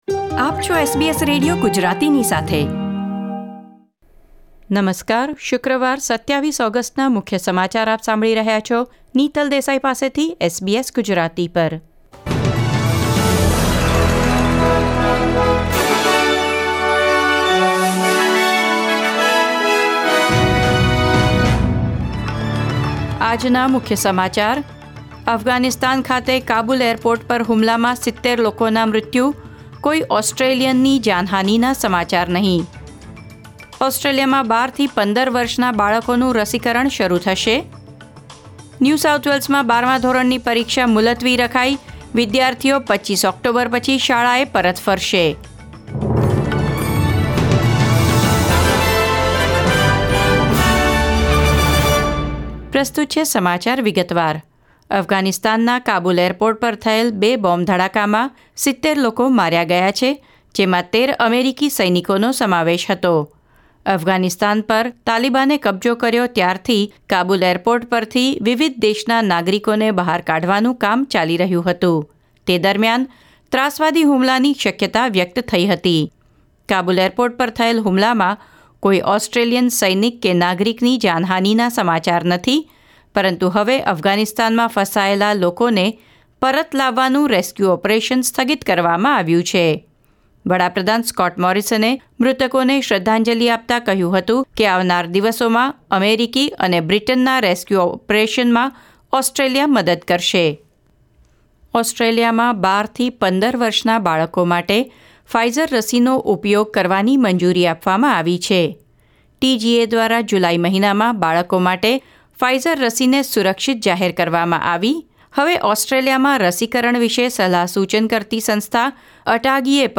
SBS Gujarati News Bulletin 27 August 2021